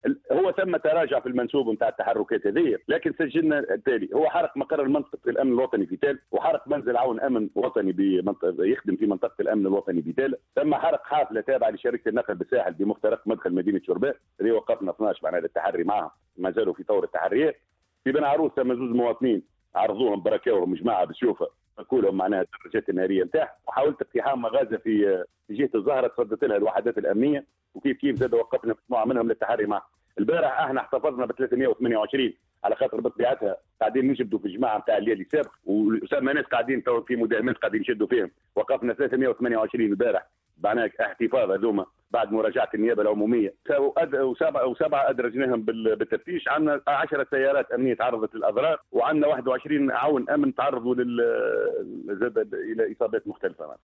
Il a précisé, dans une déclaration accordée à Mosaïque Fm, concernant les actes de violence et de vandalisme qui accompagnent ces manifestations nocturnes, que dans la nuit d’hier, un poste de sécurité a été incendié à Thala, un bus des transports publics a été incendié à l’entrée de la ville de Chorbane dans le sahel, des citoyens ont été agressés à « l’épée » et leurs motocycles volés, qu’il y a eu des tentatives de pillage de magasins. Il a ajouté, en outre, que des takfiristes ont pris part au grabuge dans certaines régions.